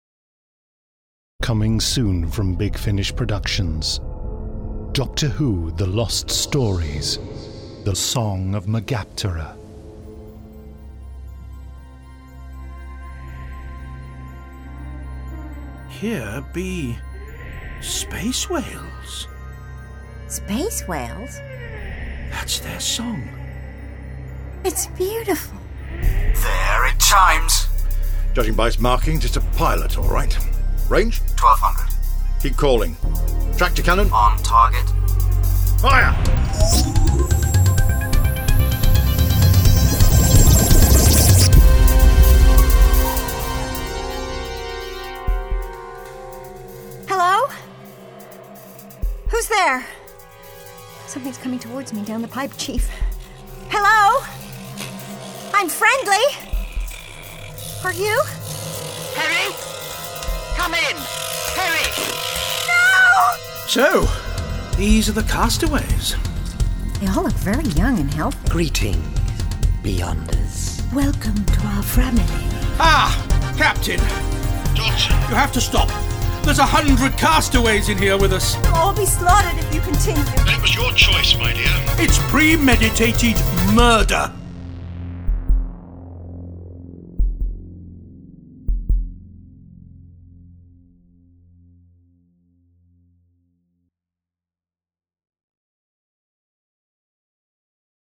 Starring Colin Baker Nicola Bryant